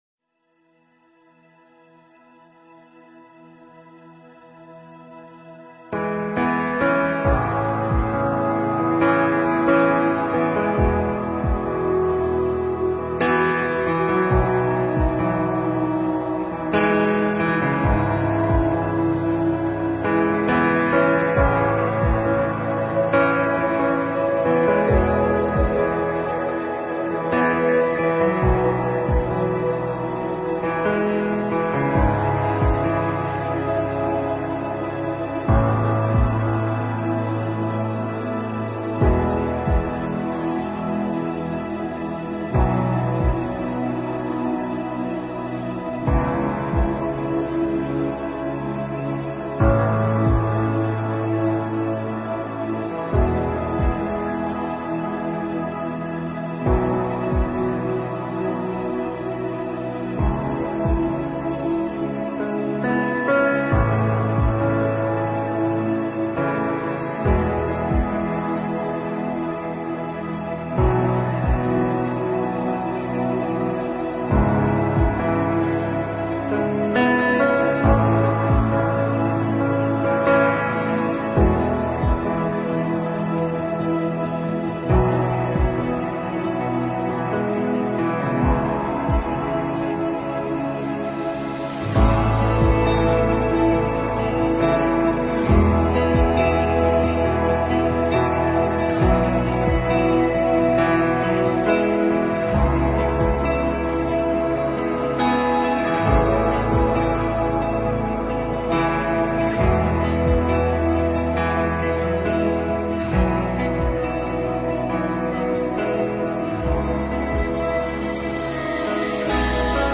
NHẠC NỀN (BEAT-KARAOKE)